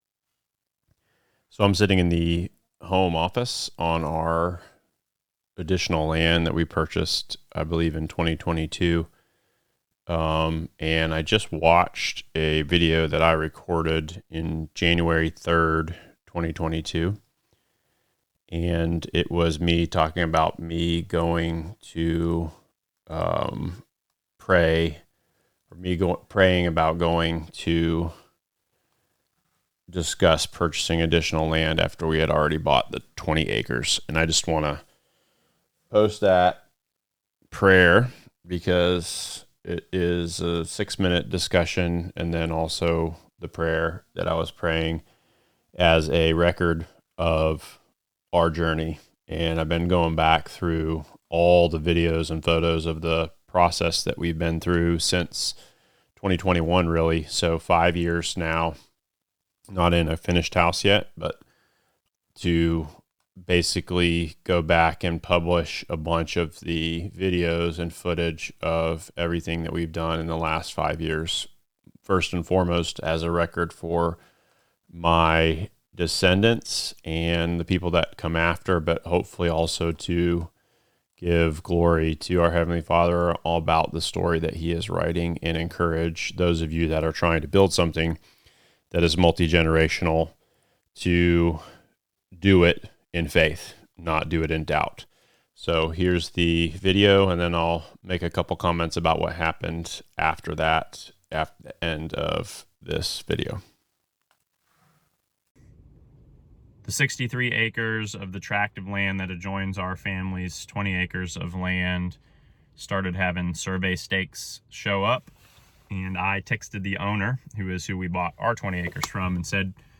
This episode is the raw, unedited prayer from 2022 and what happened after.